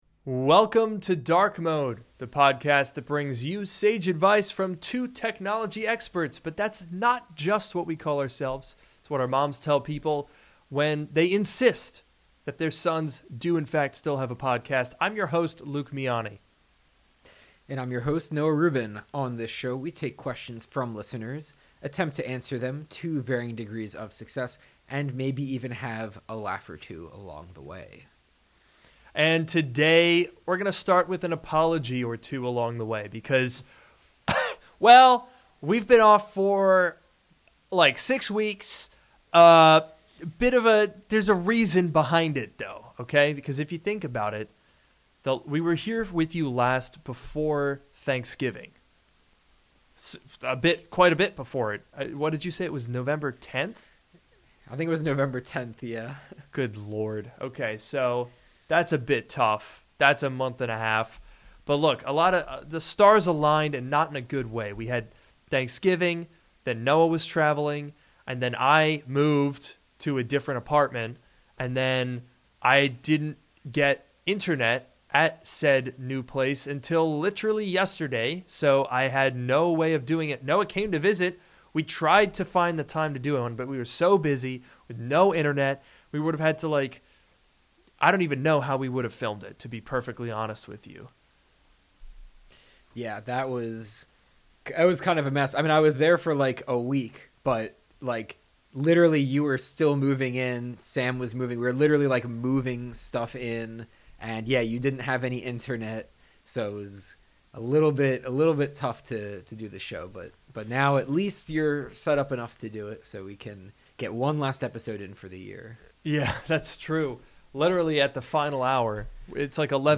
This is Drk Mode, the podcast that brings you sage advice from two technology experts.